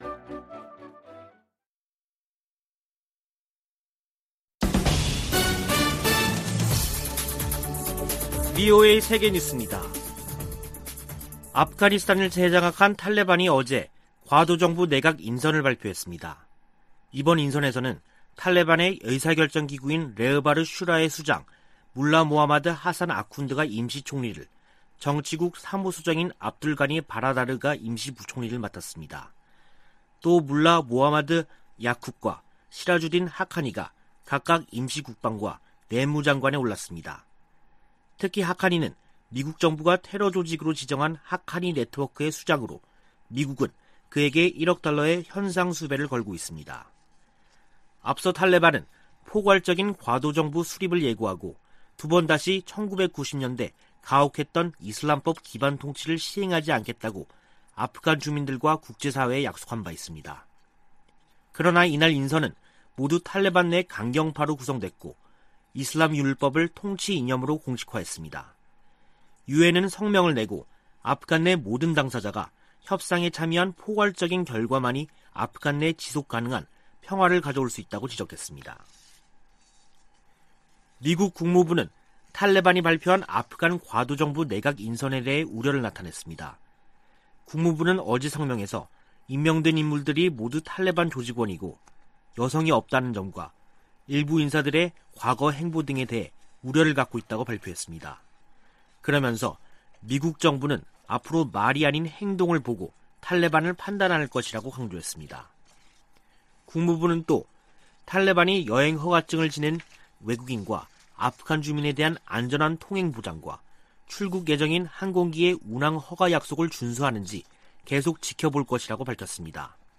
VOA 한국어 간판 뉴스 프로그램 '뉴스 투데이', 2021년 9월 8일 2부 방송입니다. 북한이 9일 열병식을 개최한다면 신형 무기 등장 여부가 최우선 관심사 가운데 하나라고 미국의 전문가들은 밝혔습니다. 미국인 10명 중 5명은 북한의 핵 프로그램에 대해 크게 우려하는 것으로 나타났습니다. 중국과 러시아가 유엔 안전보장이사회에서 대북 제재 완화 분위기를 띄우고 있는 가운데 미국은 제재를 계속 이행할 것이라는 입장을 밝혔습니다.